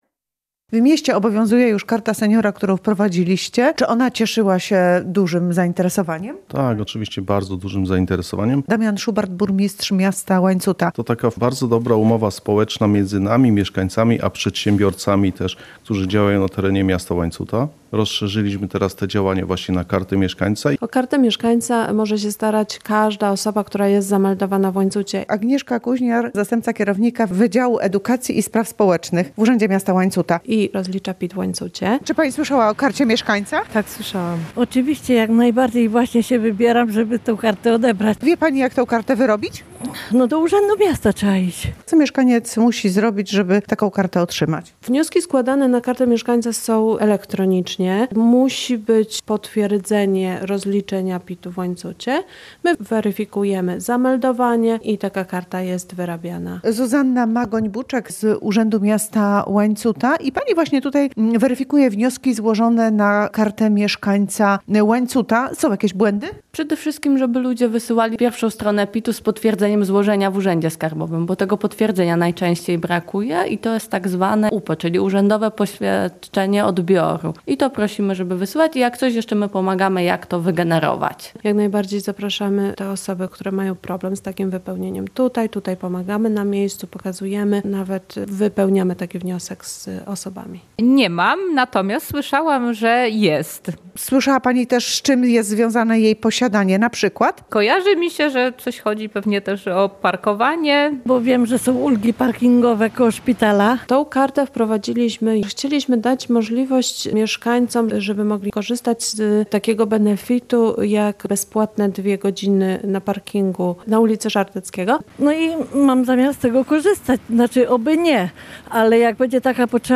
Darmowe parkowanie i nowe ulgi • Relacje reporterskie • Polskie Radio Rzeszów